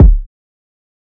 kick 15.wav